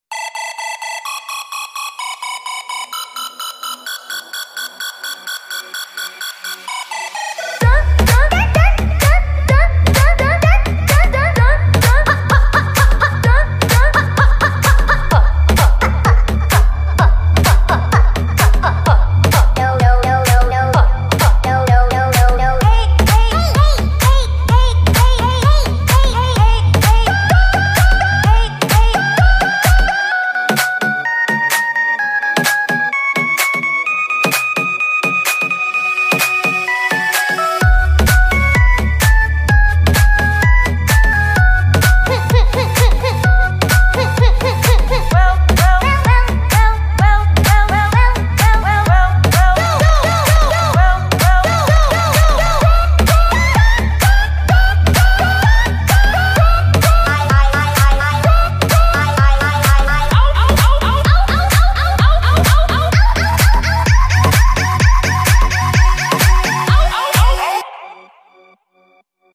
so cute dinosaur toy with sound effects free download
so cute dinosaur toy with music and light